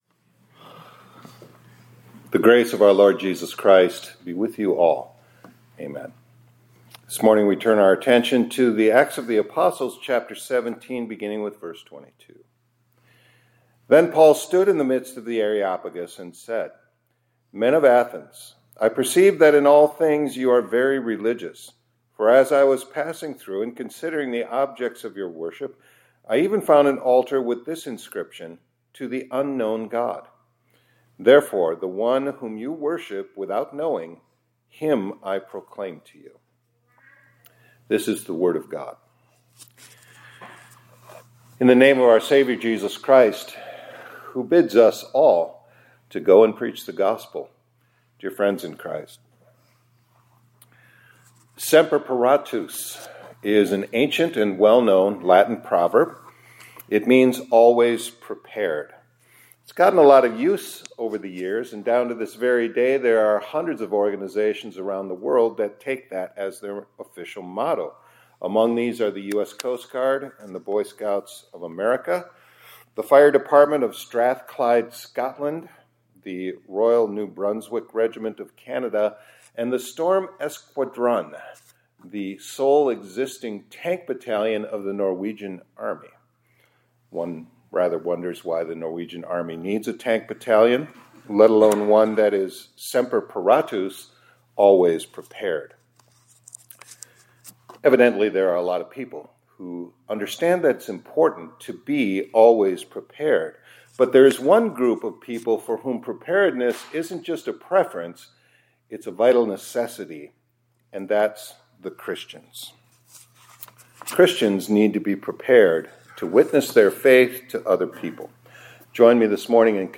2026-02-04 ILC Chapel — SEMPER PARATUS — ALWAYS PREPARED: With a Care for Souls